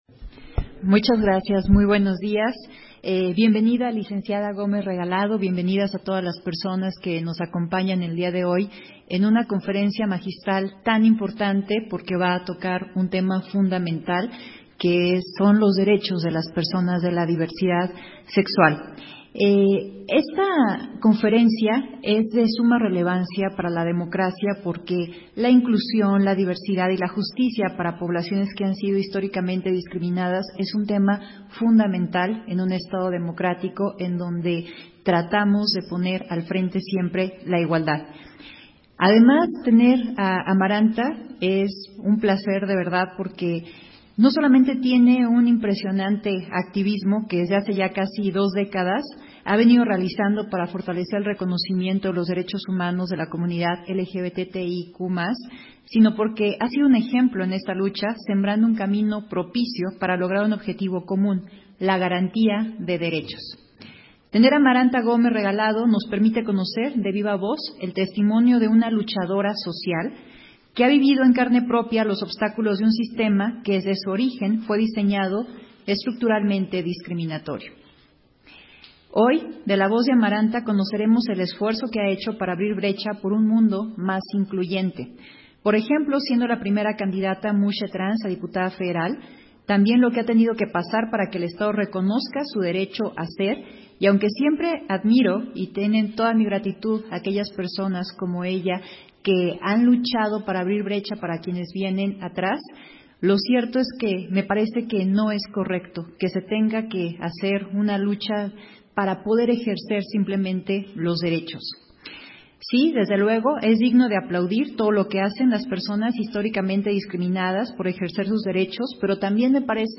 Versión estenográfica de la Conferencia Magistral Especial: Democracia e inclusión de las personas LGBTTTIQ+